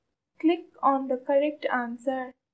click.mp3